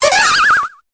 Cri de Mime Jr. dans Pokémon Épée et Bouclier.